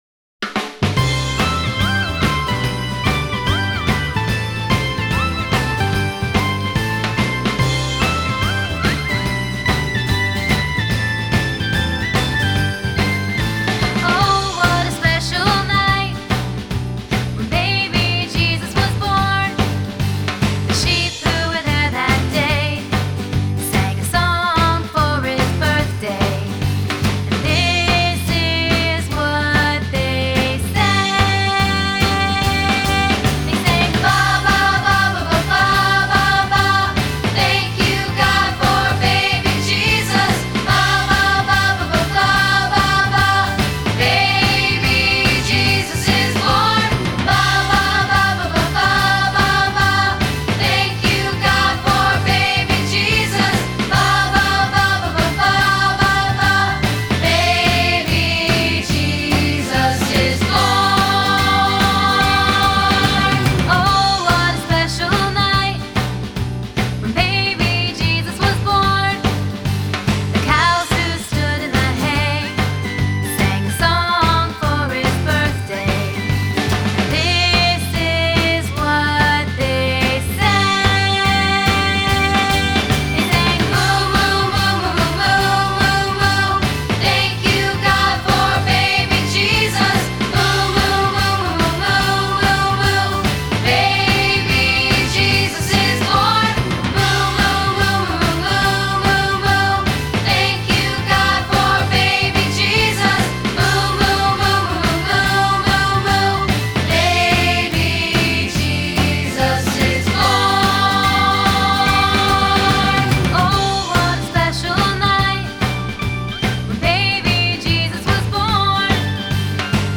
Lakeside Baptist Church - Alabama - Choir